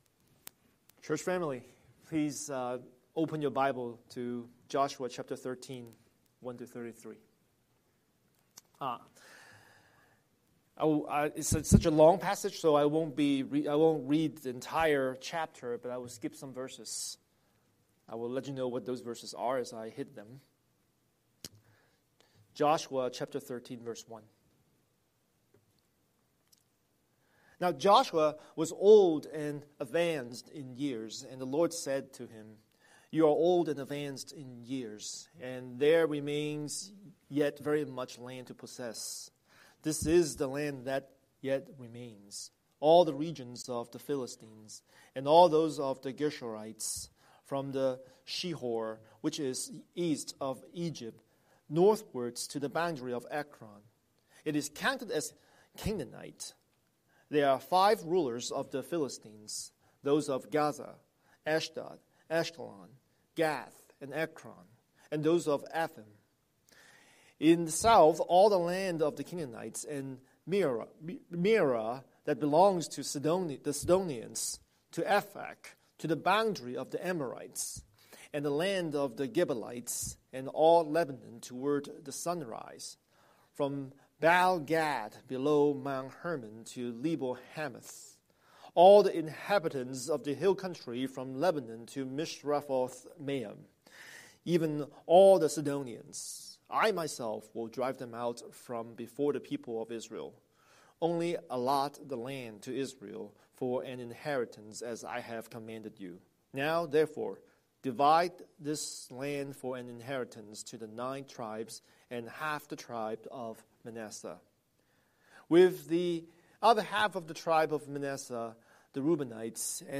Scripture: Joshua 13:1-33 Series: Sunday Sermon